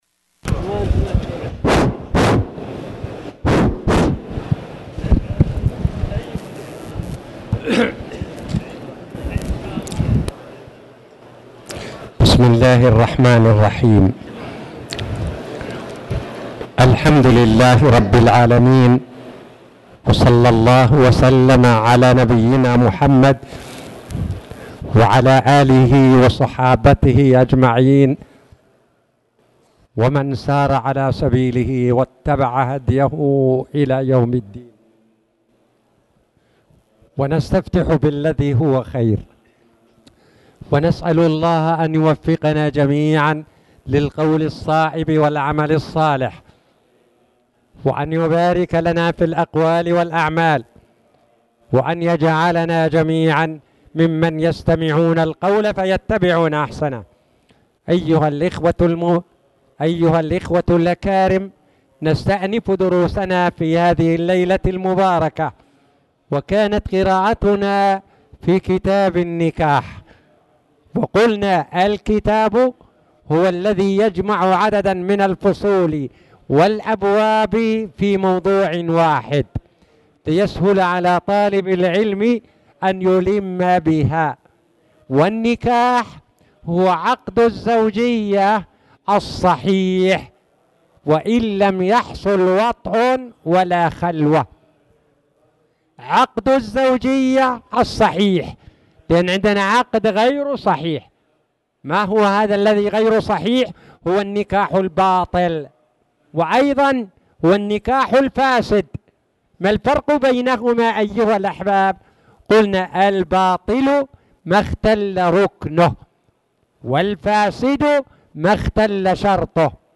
تاريخ النشر ١٠ ربيع الثاني ١٤٣٨ هـ المكان: المسجد الحرام الشيخ